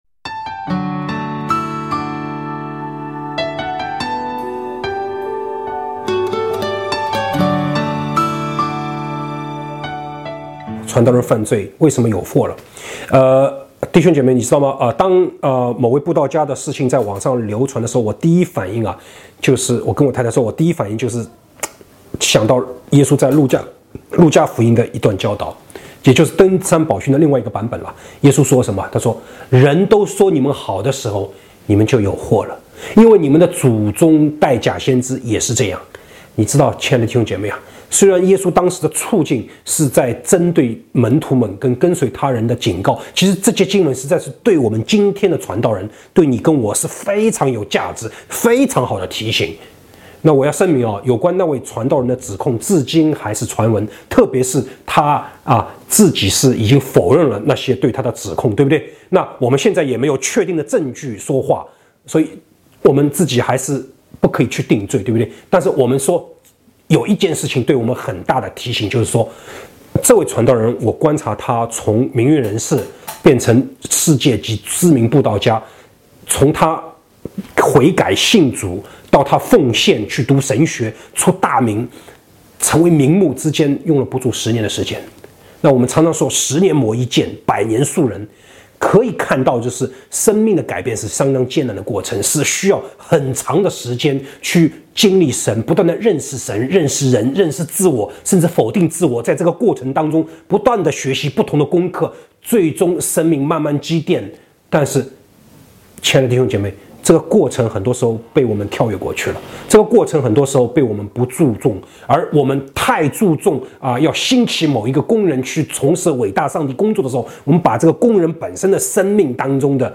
讲员：